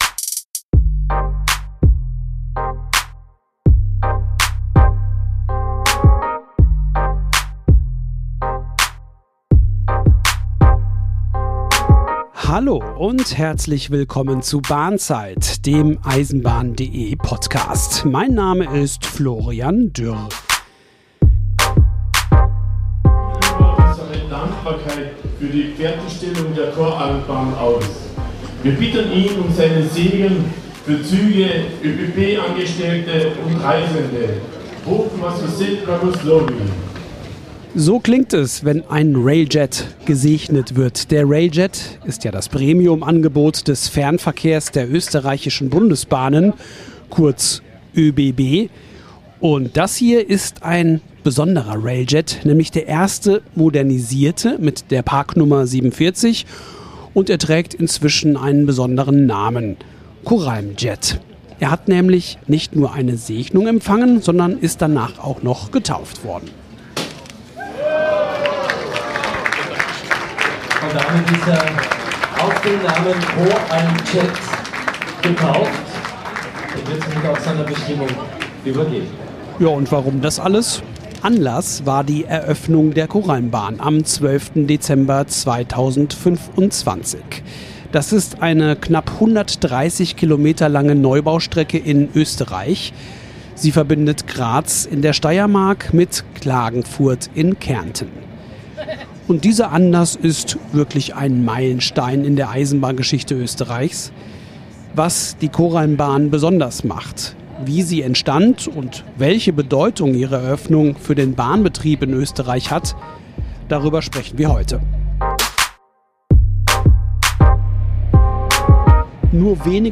auf der Premierenfahrt durch den 33 Kilometer langen Koralmtunnel